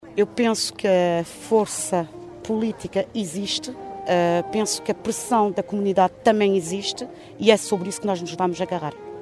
Cerca de meia centena de pessoas reuniram-se ontem ao final da tarde em Caminha para uma vigília a favor da manutenção do Centro de Acolhimento Temporário Benjamim (CAT) de Seixas, cujo encerramento por parte da APPACDM, está previsto para finais de junho.